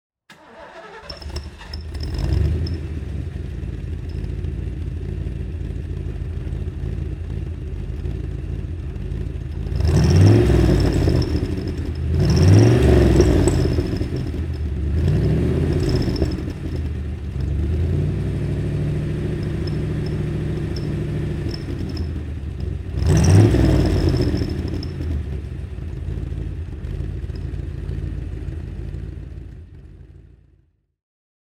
Motorsounds und Tonaufnahmen zu ISO Fahrzeugen (zufällige Auswahl)
Iso Grifo IR-8 (1973) - Starten und Leerlauf